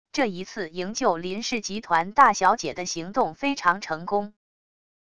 这一次营救林氏集团大小姐的行动非常成功wav音频生成系统WAV Audio Player